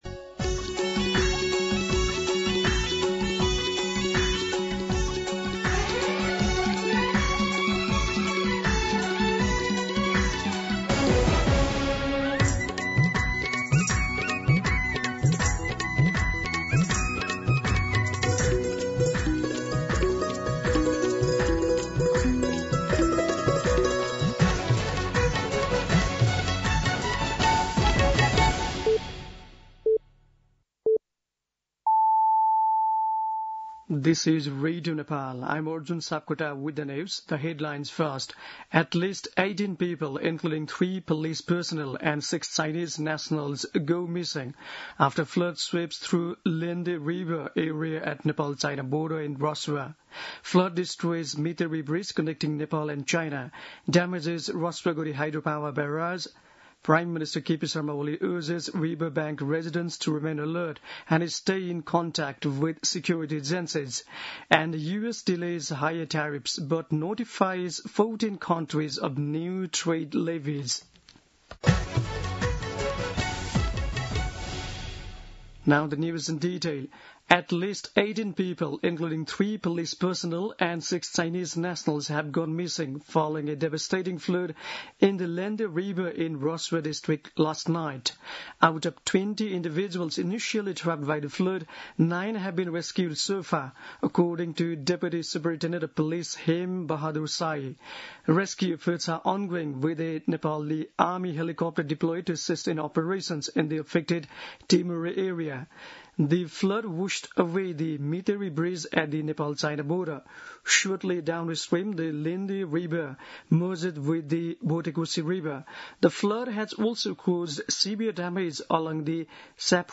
दिउँसो २ बजेको अङ्ग्रेजी समाचार : २४ असार , २०८२
2pm-English-News-24.mp3